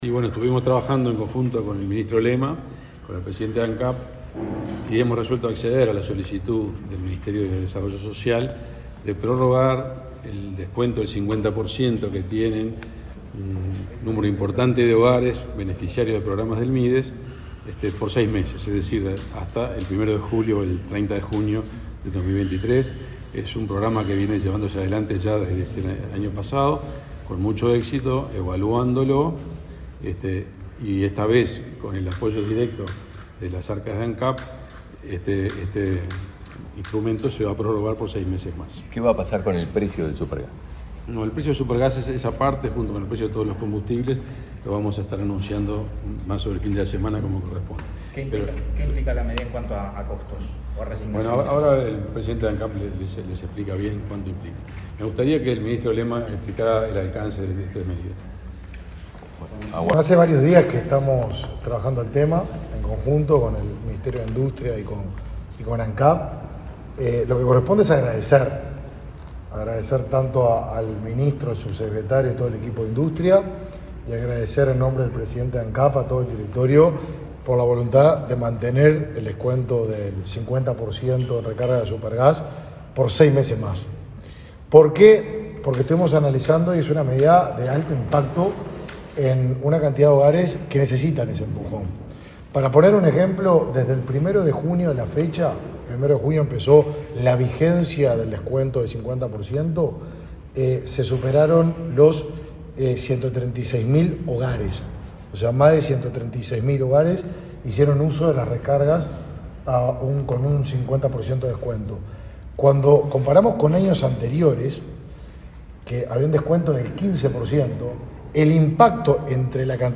Palabra de autoridades de los ministerios de Industria y Desarrollo Social, y Ancap
El ministro de Industria, Energía y Minería, Omar Paganini; su par de Desarrollo Social, Martín Lema, y el presidente de Ancap, Alejandro Stipanicic,